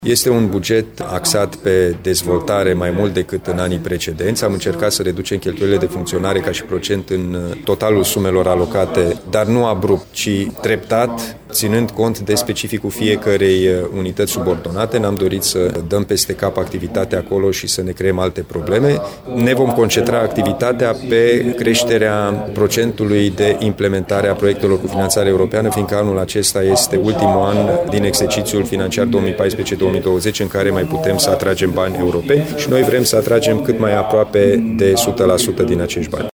Bugetul judeţului se ridică la 860 de milioane de lei, spune președintele Consiliului Județean Timiş, Alin Nica.
05-Alin-Nica-buget.mp3